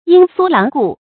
鷹脧狼顧 注音： ㄧㄥ ㄙㄨㄛ ㄌㄤˊ ㄍㄨˋ 讀音讀法： 意思解釋： 見「鷹視狼顧」。